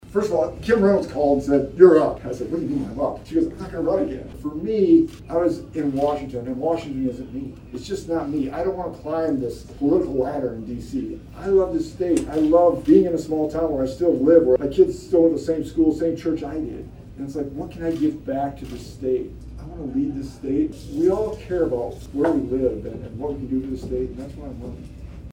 A group gathered inside one of the back dining areas at Pizza Ranch while Feenstra gave his speech on his campaign run.
Feenstra then opened up the floor for questions or concerns that individuals would like to see address. The first question raised was why Feenstra was looking to run for governor instead of remaining in congress.